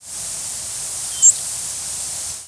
presumed Song Sparrow nocturnal flight call
Fox or White-crowned Sparrow are also possibilities here.